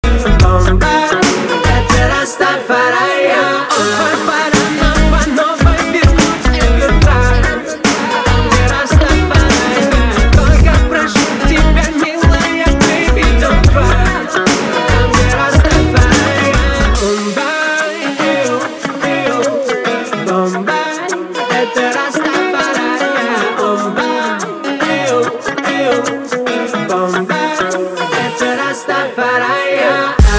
мужской вокал
рэп
регги